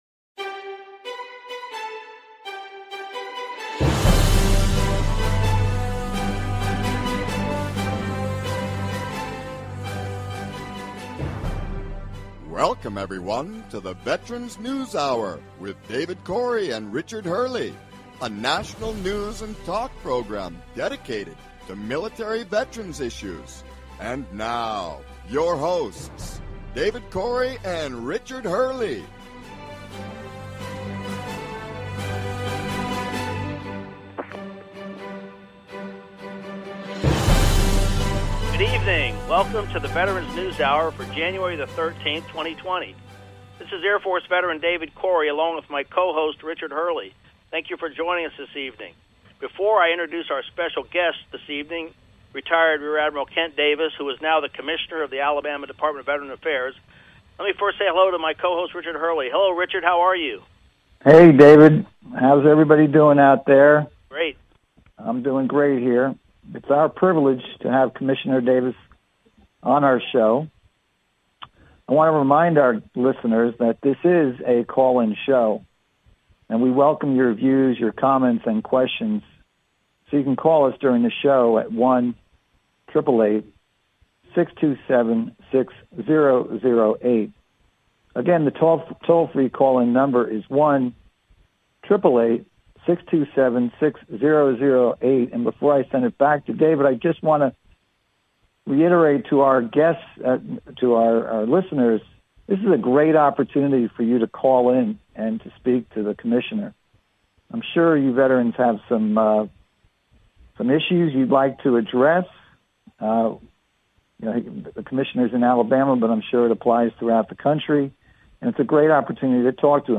Guest, Rear Admiral Kent Davis US Navy Retired, Commissioner of the Alabama Department of Veterans Affairs